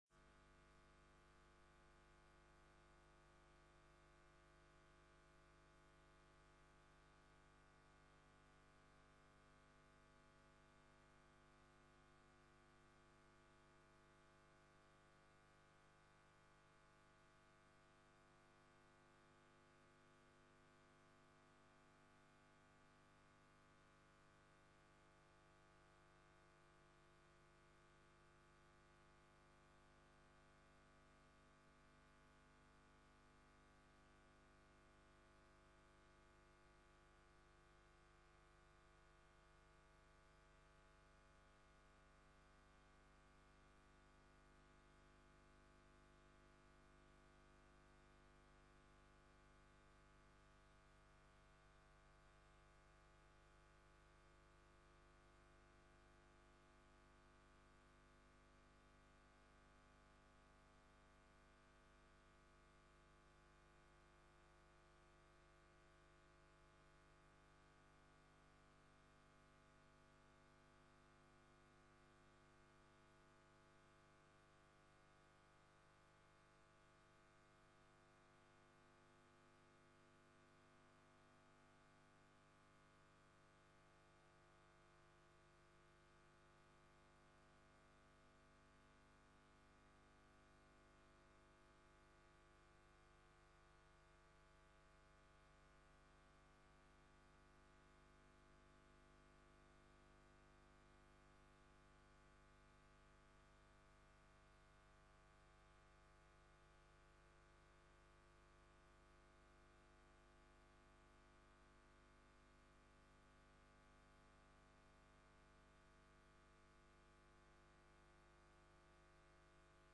Locatie: Raadzaal